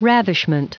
Prononciation du mot ravishment en anglais (fichier audio)
Prononciation du mot : ravishment